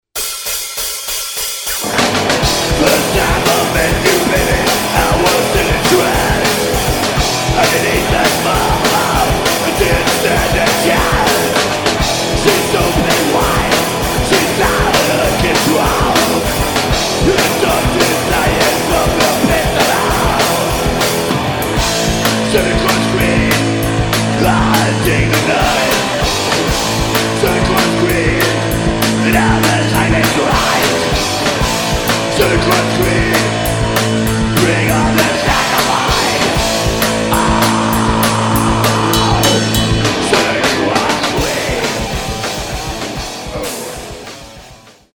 Canadian Black Speed Metal at its finest!!!